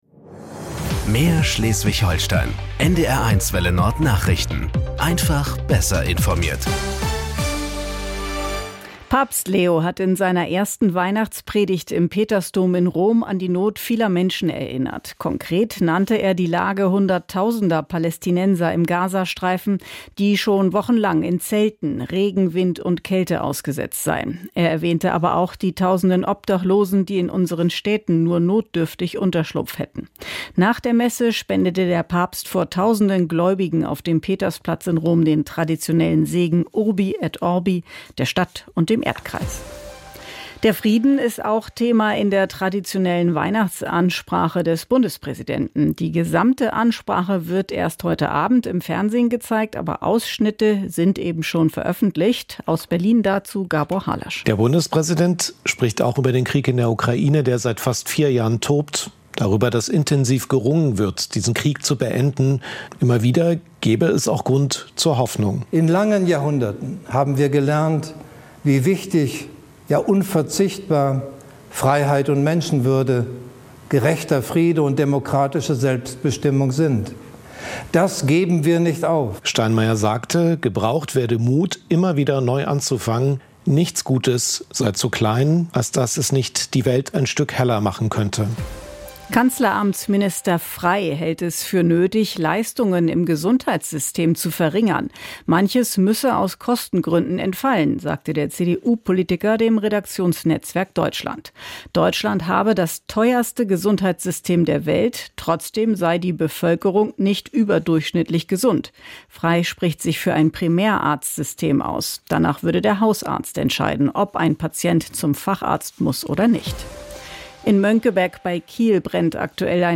Nachrichten 18:00 Uhr - 25.12.2025 ~ NDR 1 Welle Nord – Nachrichten für Schleswig-Holstein Podcast